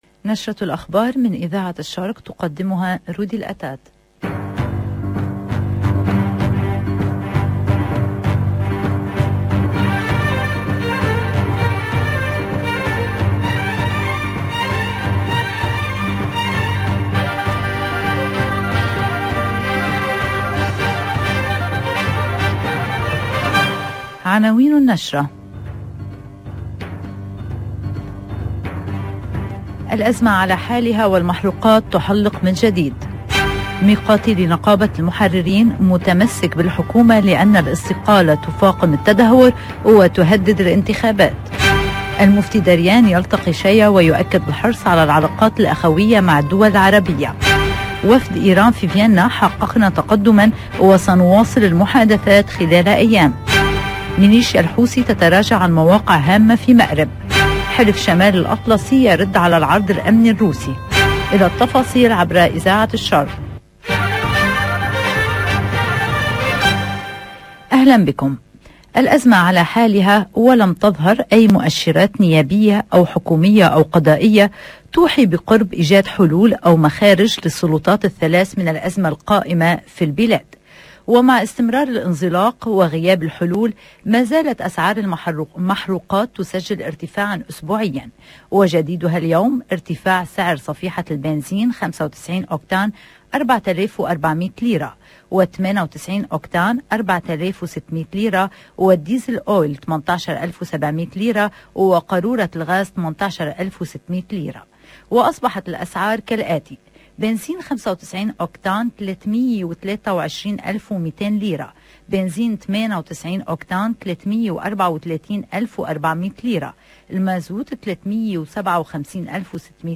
EDITION DU JOURNAL DU LIBAN DE 17H